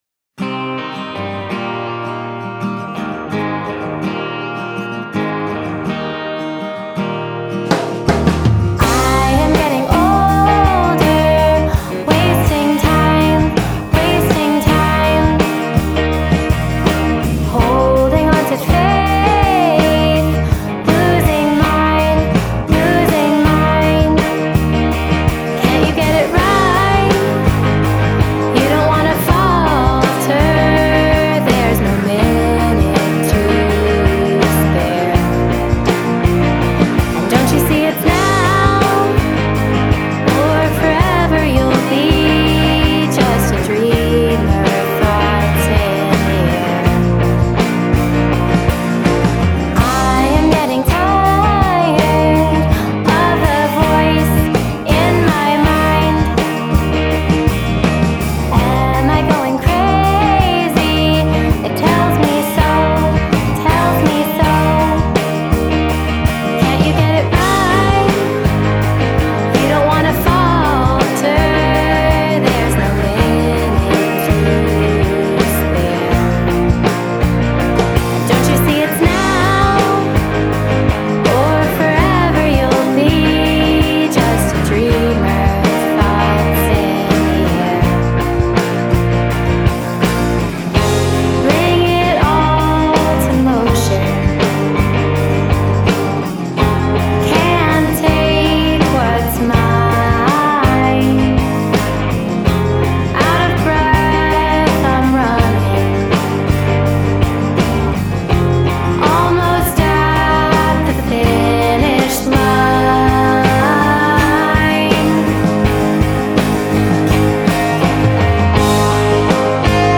UK-based expat singer/songwriter